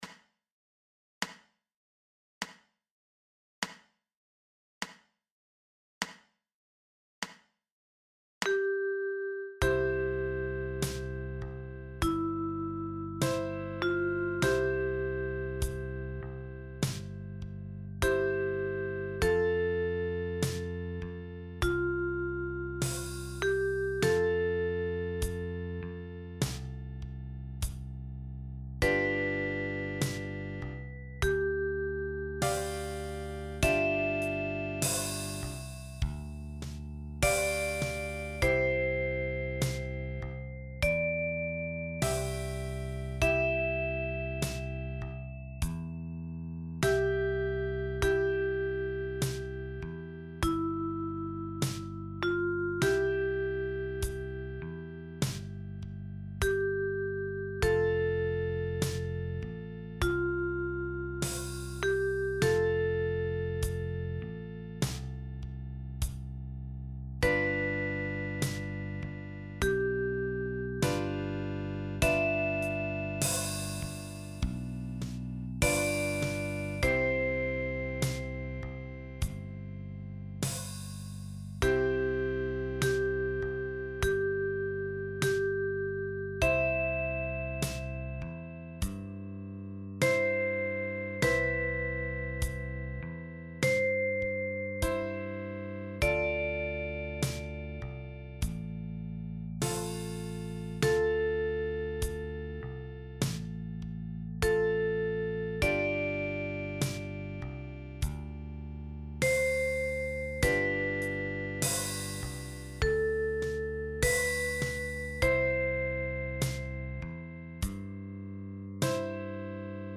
29 Seemannslieder aus aller Welt